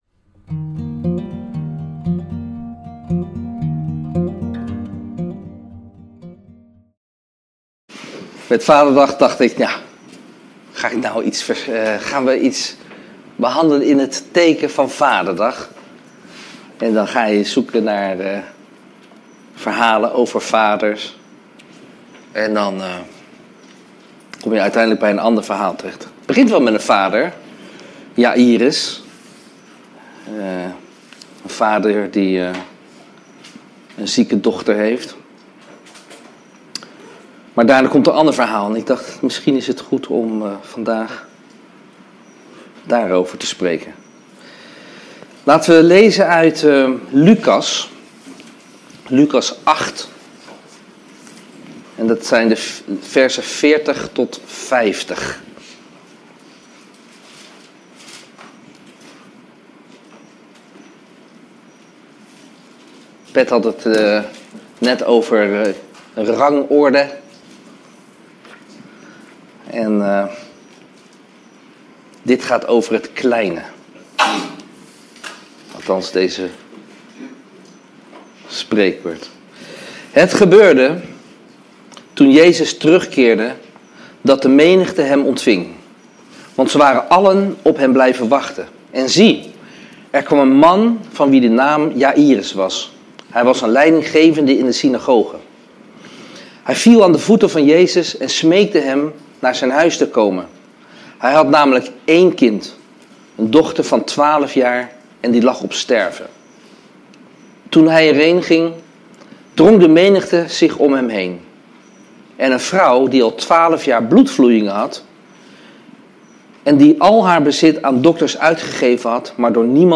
Preken